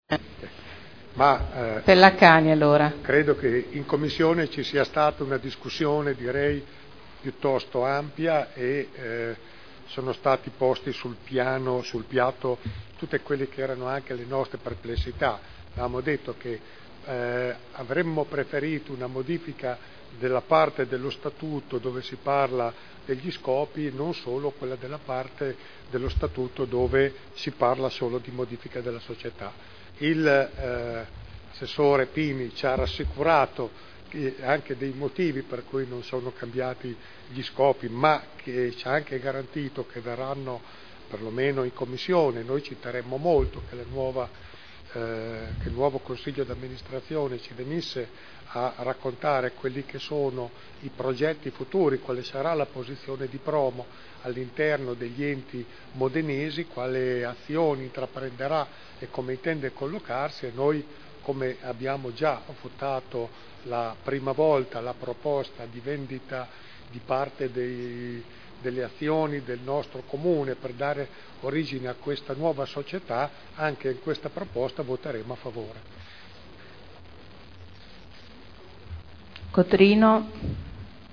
Giancarlo Pellacani — Sito Audio Consiglio Comunale
Promo s.c.a.r.l. – Approvazione modifiche statutarie Dichiarazioni di voto